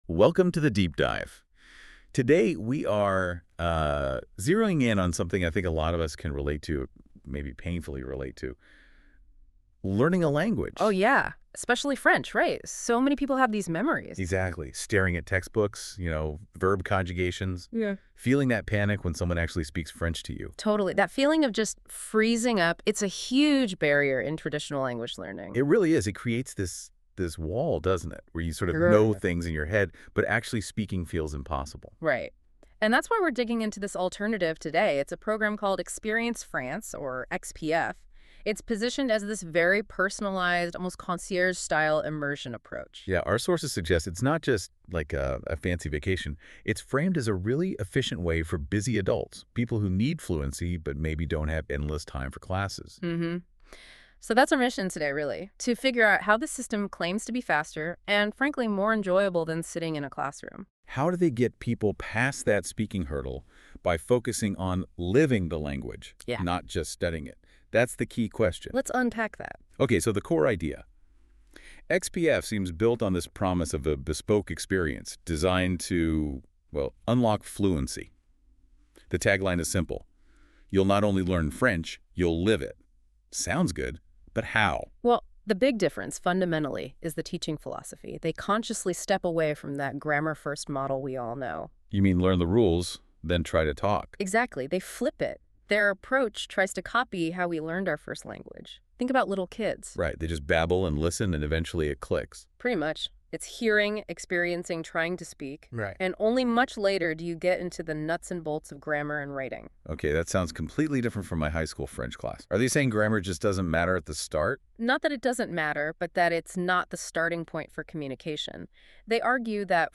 In this conversation, we explore how adults can learn French the natural way, by listening first, experiencing language in context, and speaking without fear.